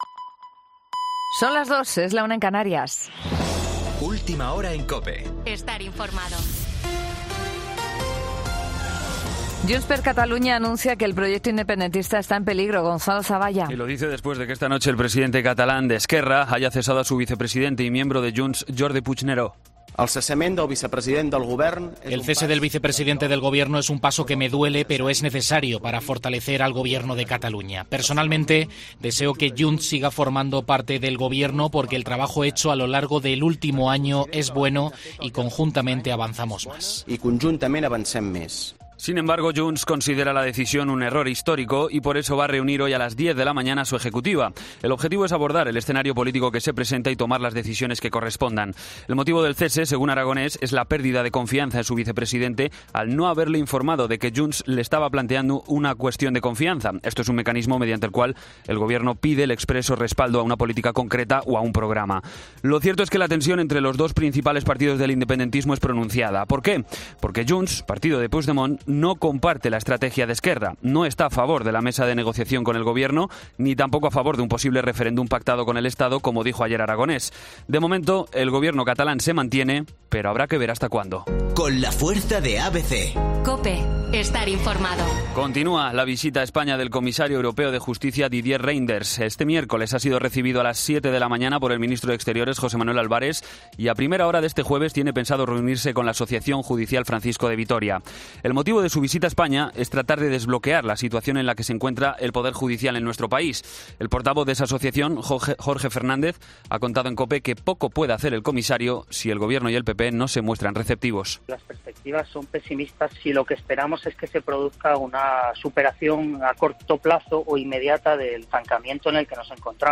Boletín de noticias COPE del 29 de septiembre a las 02:00 hora
AUDIO: Actualización de noticias Herrera en COPE